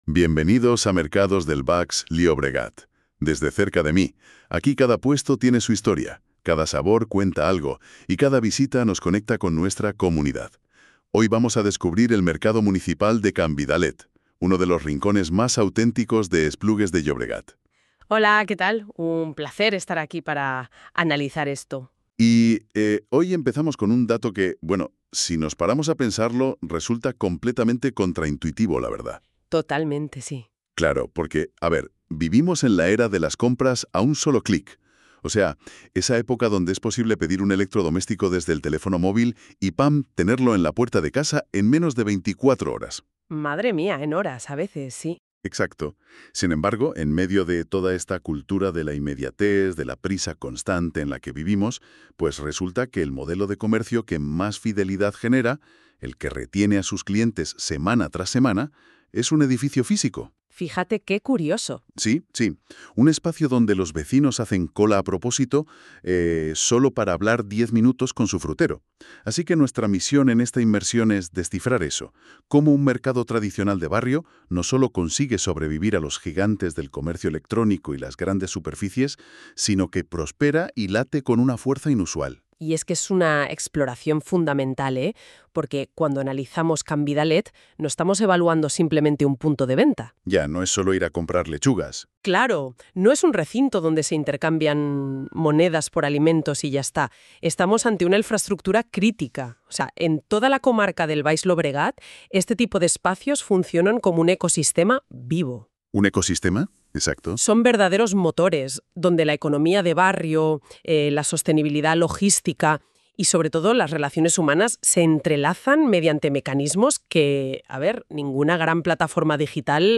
Conversaciones Sobre Comercio, Actualidad y Futuro Local.